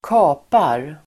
Uttal: [²k'a:par]